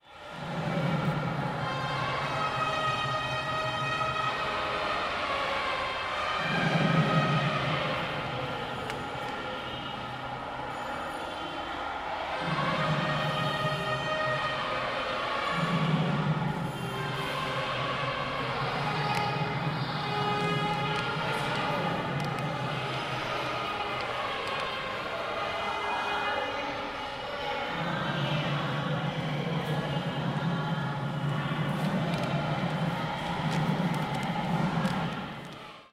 Dans Mission street, un temple surgit, tel une pièce montée multicolore, au-dessus des magasins de motos et de tee-shirts.
À l’intérieur, un musicien frappe un tambour, un autre souffle dans une conque, et les files d'attente s'organisent devant des autels couverts d'or.